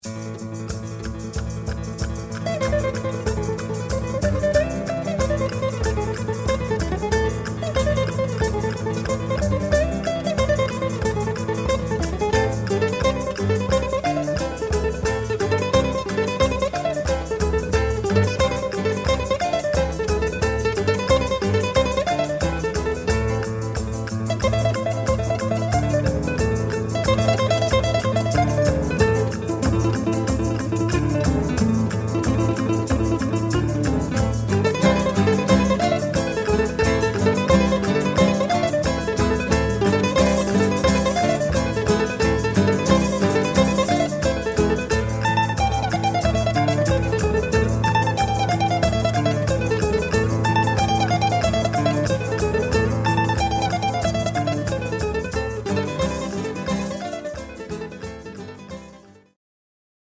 Chitarra e canzoni popolari del sud